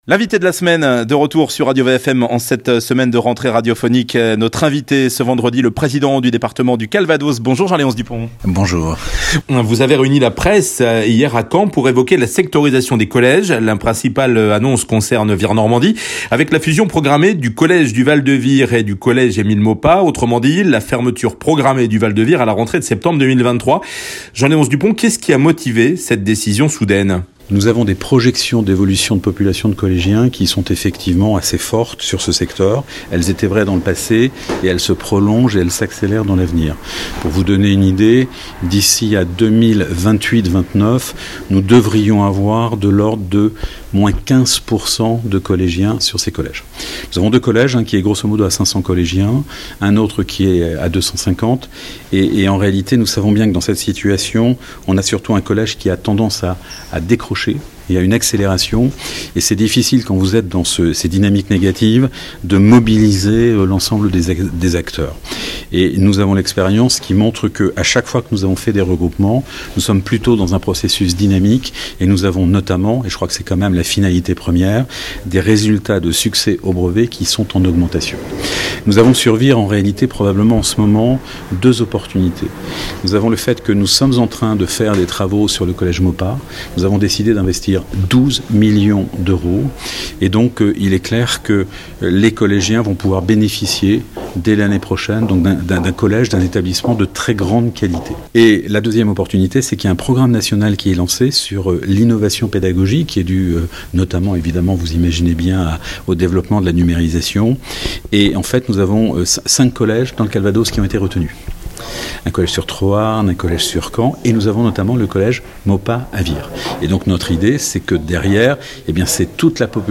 Au lendemain de l’annonce de la fermeture du collège du Val de Vire, le président du Conseil Départemental du Calvados s’exprime sur notre antenne ce vendredi à 8h20 et 12h20.